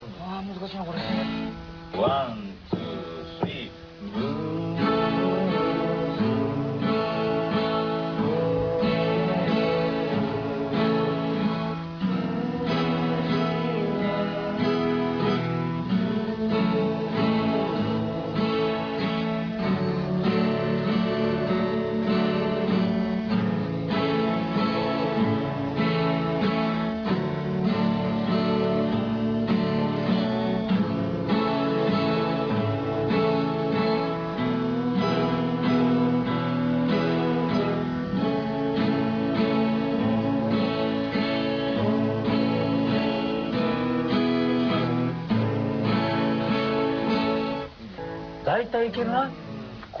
KinKi Kids with acoustic guitar